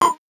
Index of /musicradar/8-bit-bonanza-samples/VocoBit Hits
CS_VocoBitC_Hit-04.wav